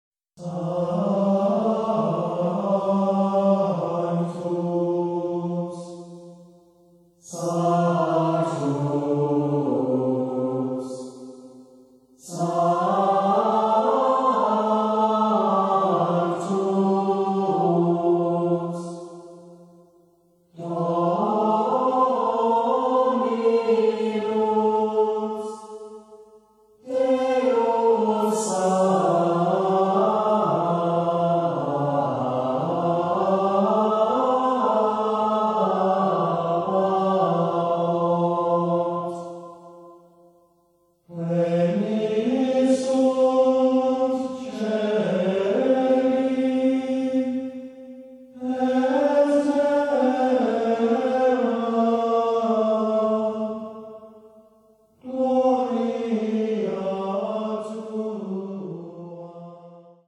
The Brotherhood Of St. Gregory Choir – The Catholic Mass
Песнопения мессы. Запись сделана в студии Landy Star Music, 2006 г.